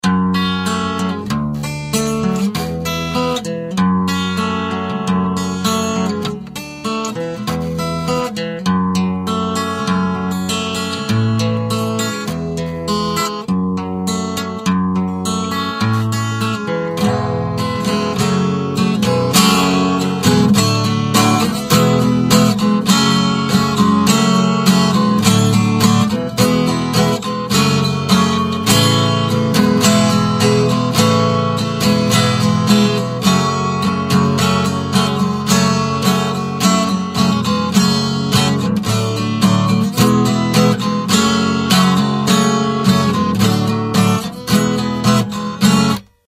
• Качество: 128, Stereo
красивые
без слов